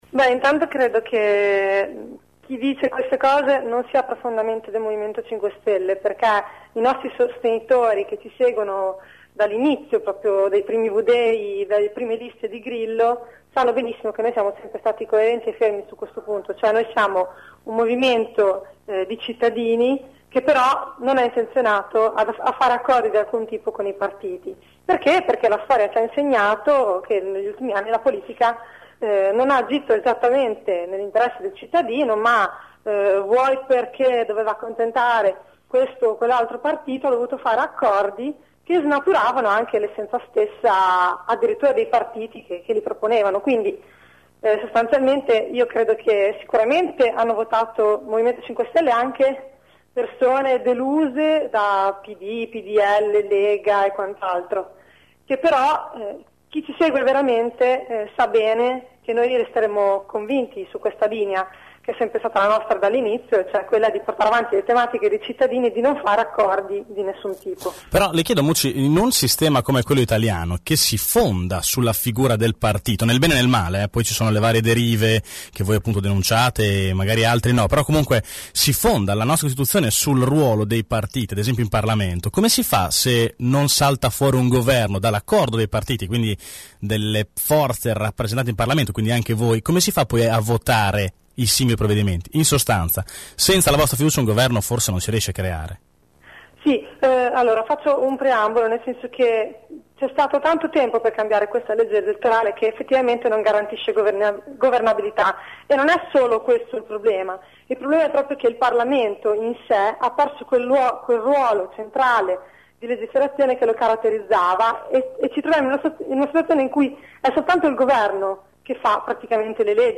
La neodeputata grillina, ospite durante AngoloB è contraria ad un accordo organico con il Pd ma si dice disponibile a sostenere un governo di centrosinistra con personalità